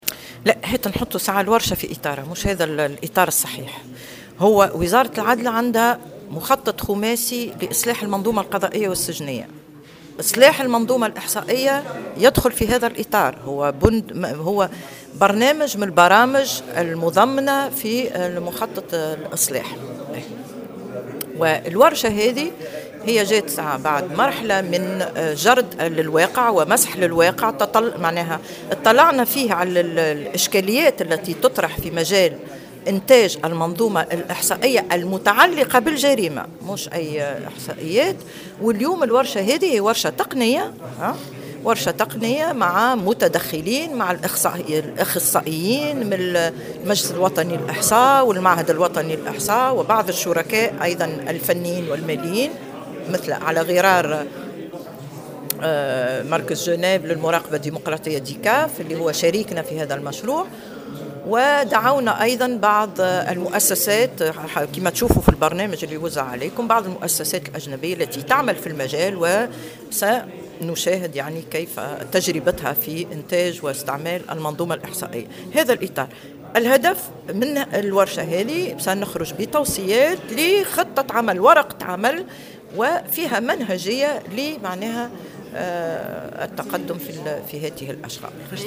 في تصريح لمراسل الجوهرة "اف ام"